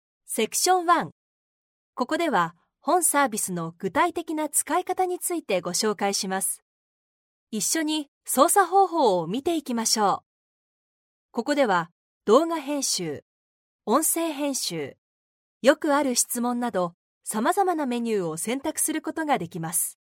Japanese voice over